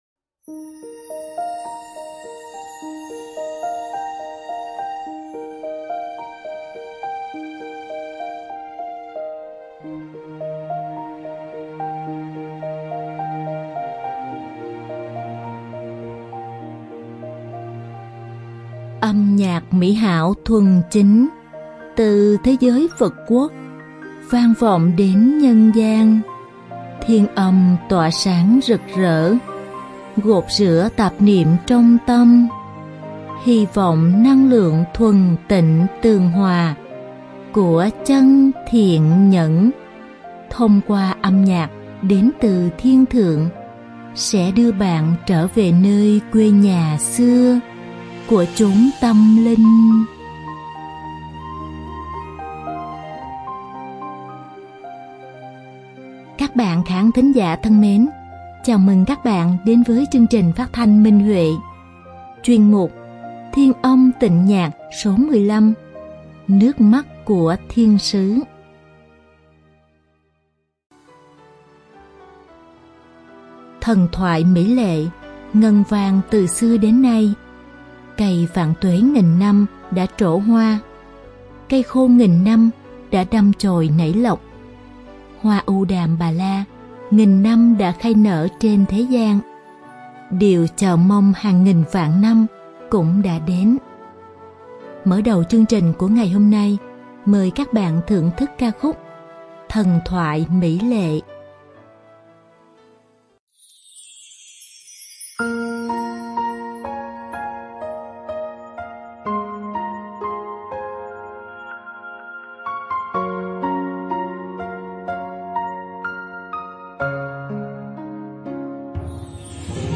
Đơn ca nữ
Màn hợp tấu sáo trúc